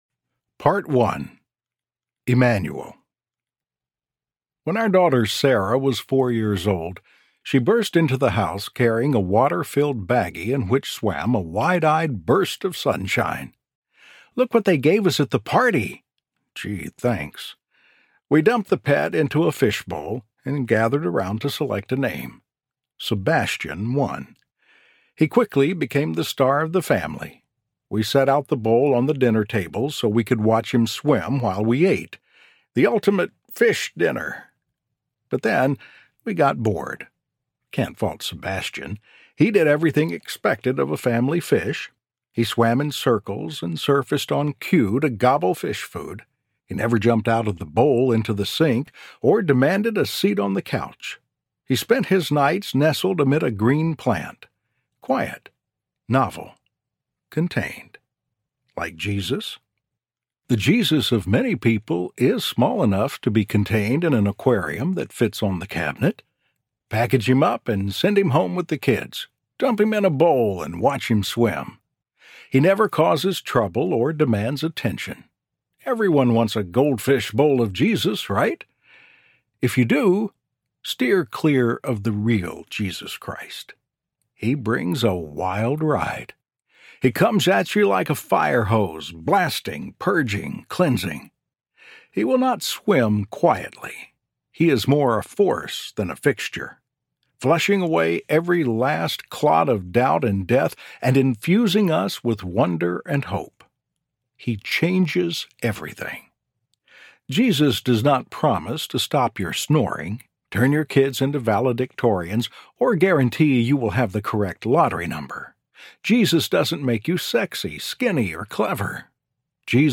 Jesus Audiobook
Narrator
7.8 Hrs. – Unabridged